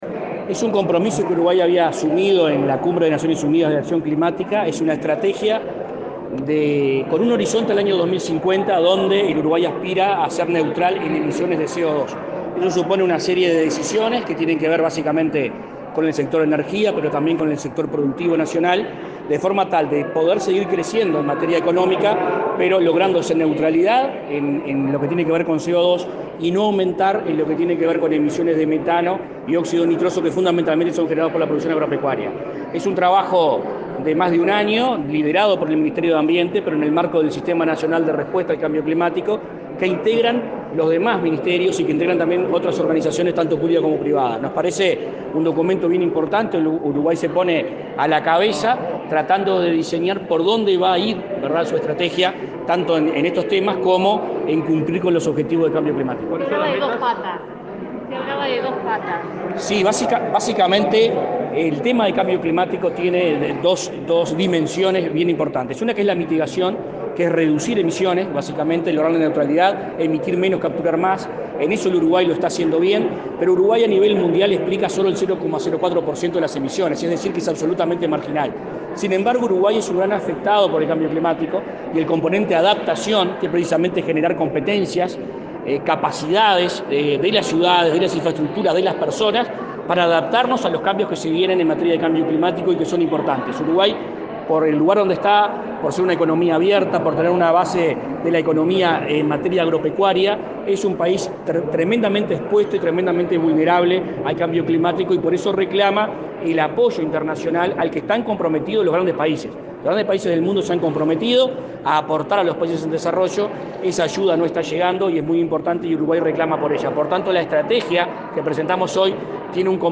Declaraciones a la prensa del ministro de Ambiente, Adrián Peña